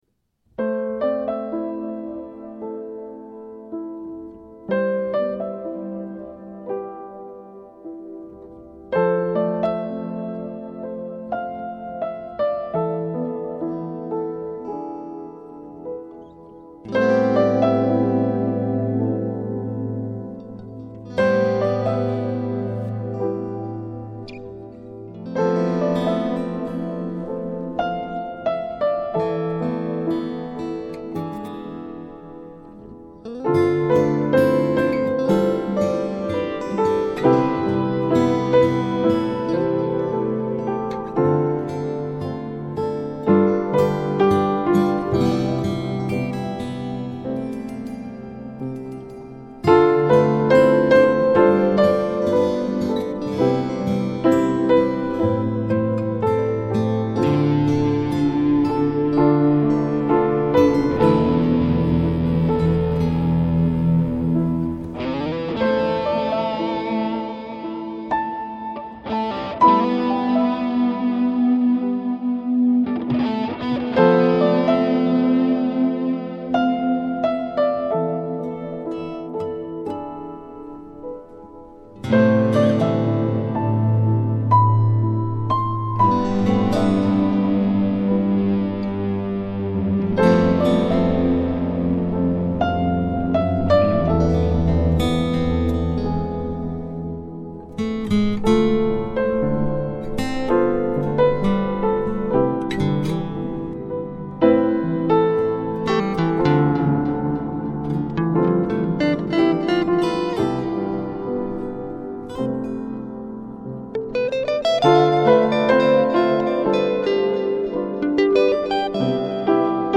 Gitarer og lydmix
piano